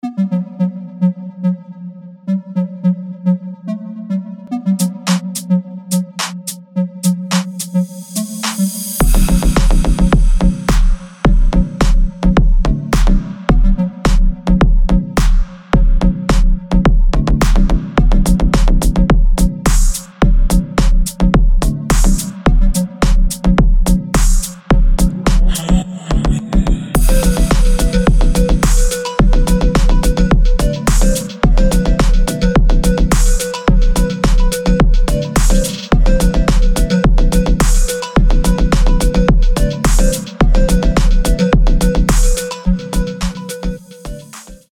• Качество: 320, Stereo
ритмичные
deep house
low bass
Bass House
G-House
Кайфовое клубное музло